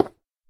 minecraft / sounds / dig / stone4.ogg
stone4.ogg